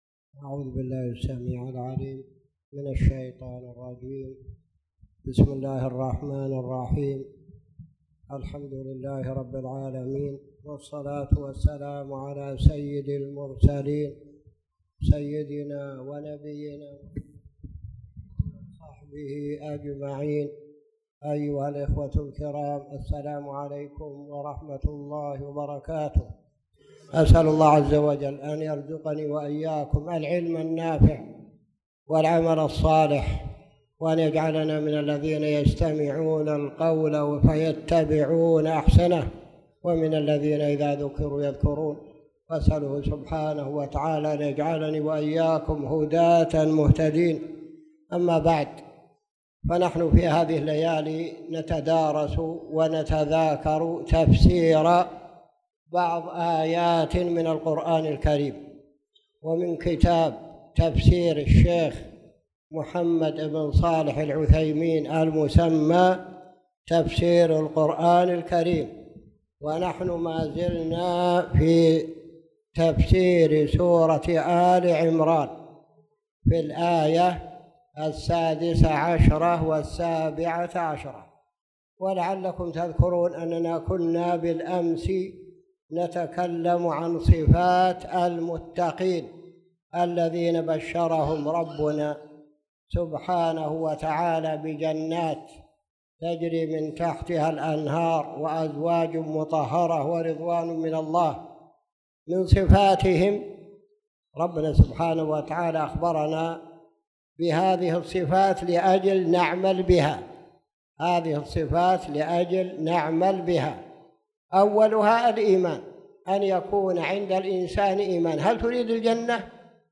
تاريخ النشر ١٨ ربيع الثاني ١٤٣٨ هـ المكان: المسجد الحرام الشيخ